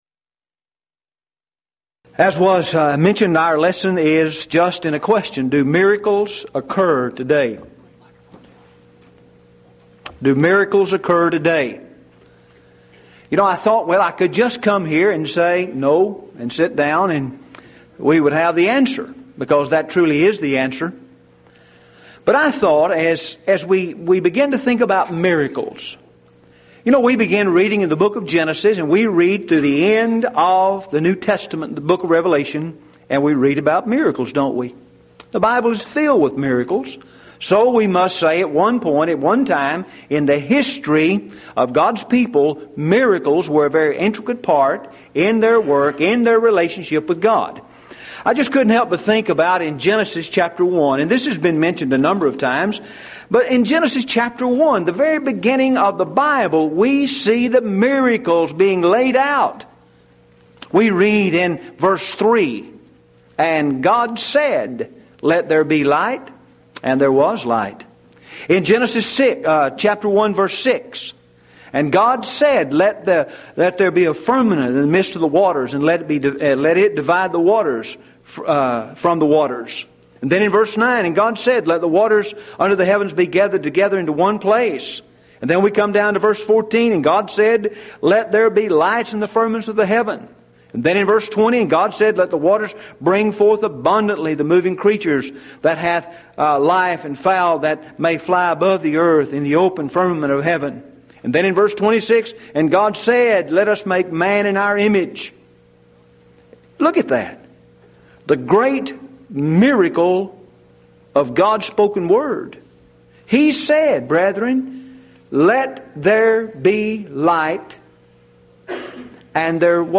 Event: 1997 Mid-West Lectures
lecture